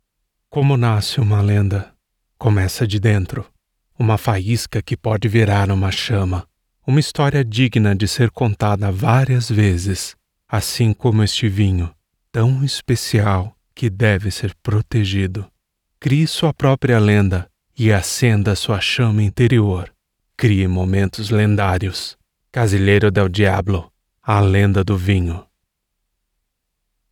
Erzählung
Meine hochmoderne Studioeinrichtung gewährleistet eine außergewöhnliche Klangqualität für jedes Projekt.
Perfekte Akustikkabine
Mikrofone sE Eletronics T2